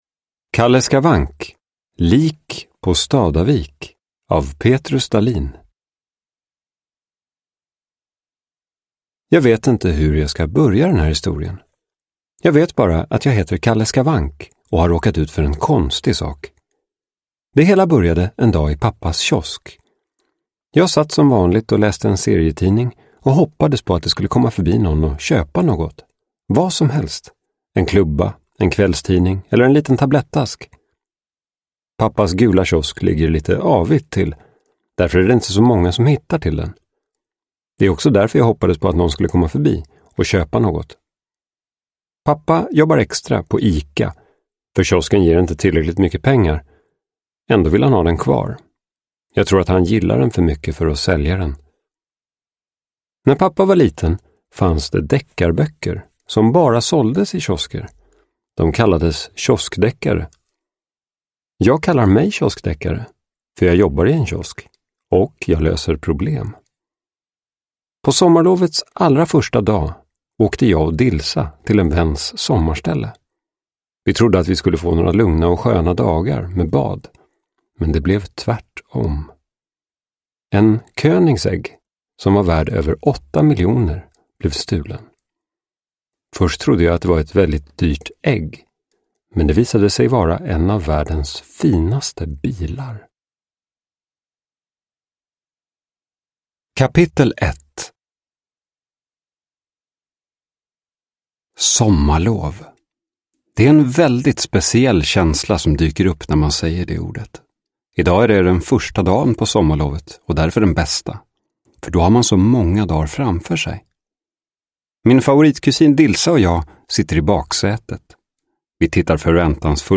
Lik på Stadavik – Ljudbok